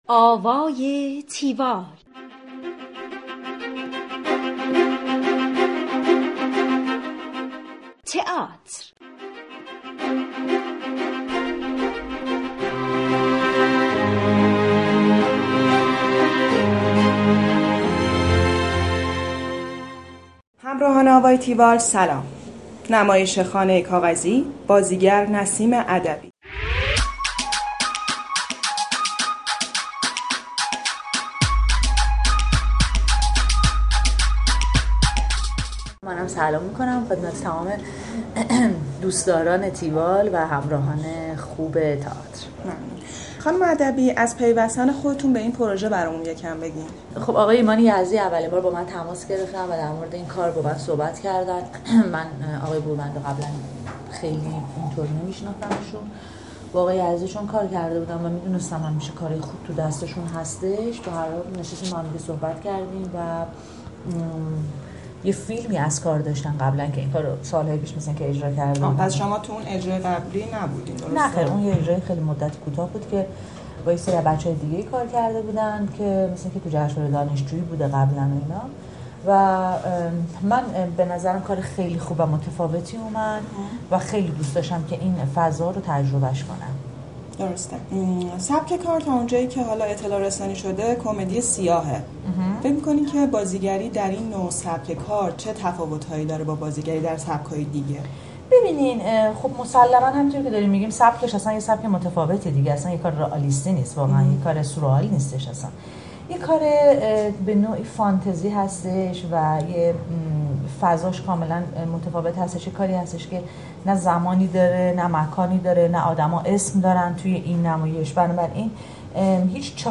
گفتگوی تیوال با نسیم ادبی
tiwall-interview-nasimadabi.mp3